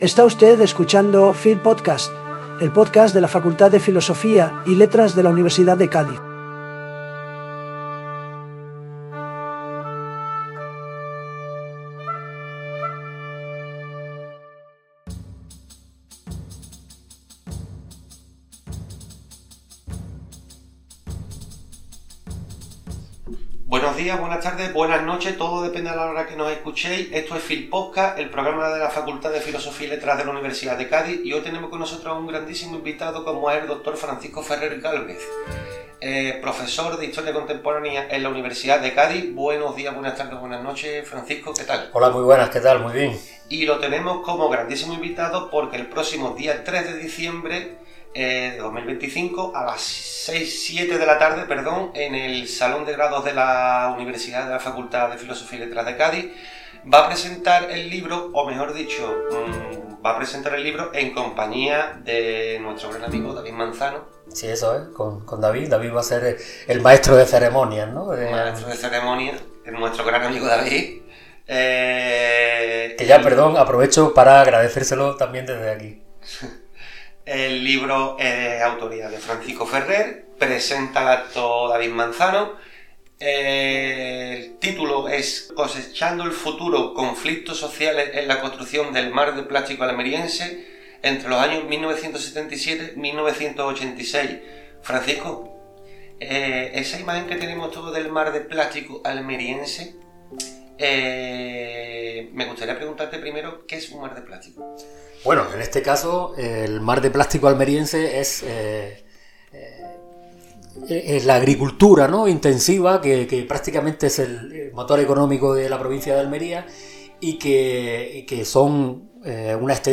Esta entrevista es una oportunidad de conocer la transferencia de conocimiento de los investigadores de nuestra Facultad desde una mirada más cercana y divulgativa.